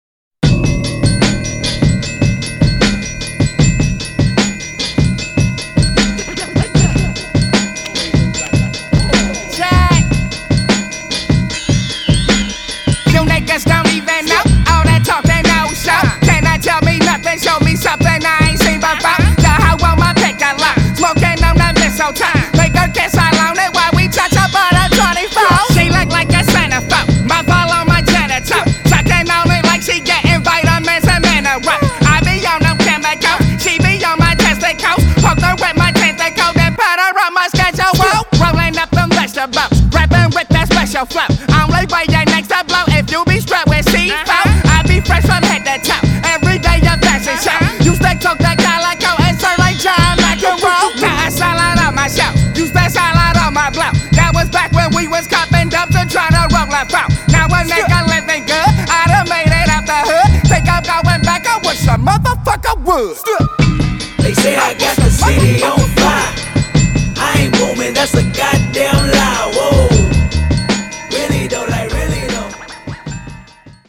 The flow is impeccable and the dexterity undeniable.